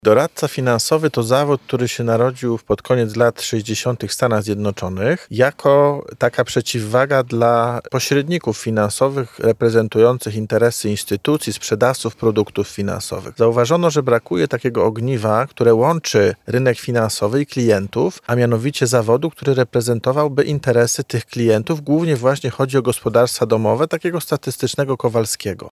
[Poranna Rozmowa] UMCS z prestiżową akredytacją EFFP Polska - Radio Centrum